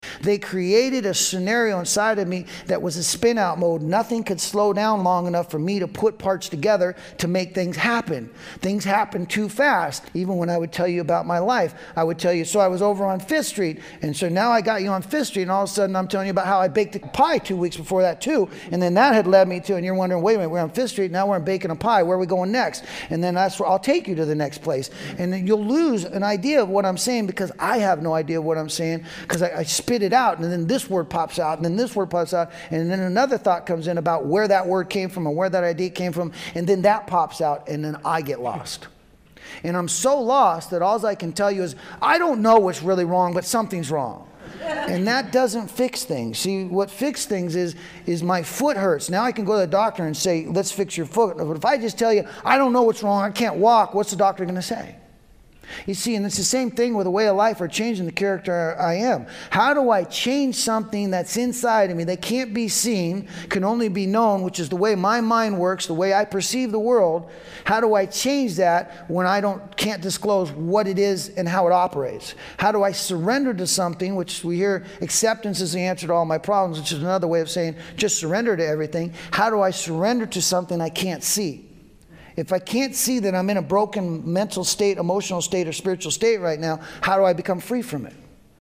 A workshop discussion on how to find inner calm and to shift negative thinking in order to build a steadier connection with a power greater than yourself.
This audio archive is a compilation of many years of lecturing.